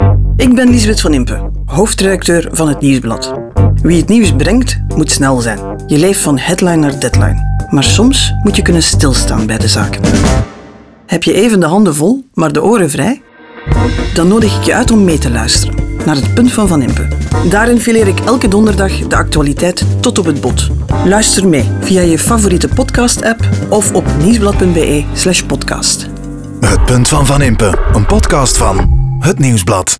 HetNieuwsblad_NL30s_PuntVanImpe_Radio.wav